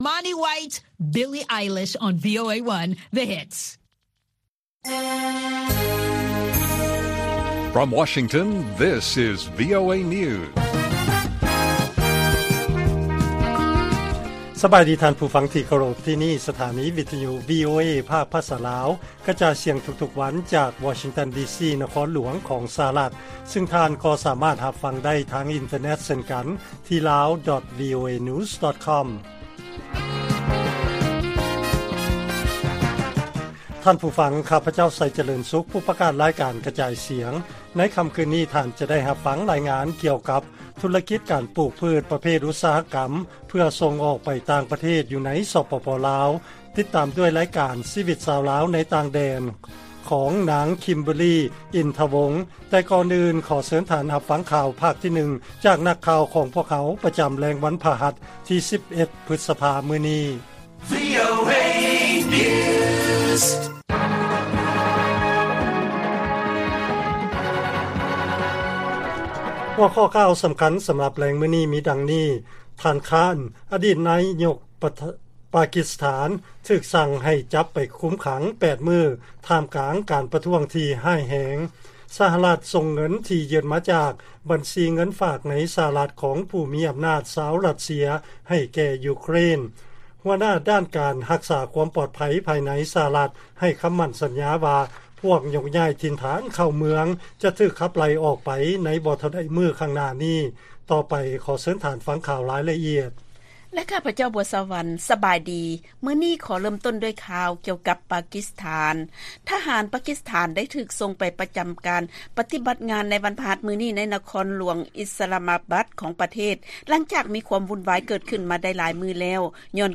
ວີໂອເອພາກພາສາລາວ ກະຈາຍສຽງທຸກໆວັນ, ຫົວຂໍ້ຂ່າວສໍາຄັນໃນມື້ນີ້ມີ: 1. ທ່ານຄານ ຖືກສັ່ງໃຫ້ຈັບໄປຄຸມຂັງ 8 ມື້ ທ່າມກາງການປະທ້ວງ ທີ່ຮ້າຍແຮງ ໃນປະເທດ, 2. ສະຫະລັດ ສົ່ງເງິນທີ່ຢຶດມາຈາກບັນຊີຂອງຊາວ ຣັດເຊຍ ໃຫ້ແກ່ ຢູເຄຣນ, ແລະ 3. ຜູ້ຮັບຜິດຊອບພາຍໃນ ໃຫ້ຄຳໝັ້ນສັນຍາວ່າ ພວກຍົກຍ້າຍເຂົ້າເມືອງຈະຖືກຂັບໄລ່ອອກໄປໃນໄວໆນີ້.